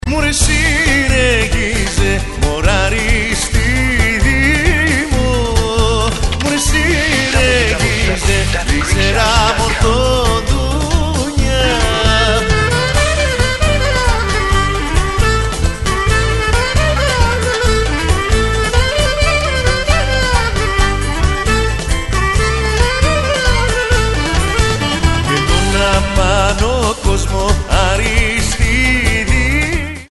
non-stop traditional Greek hits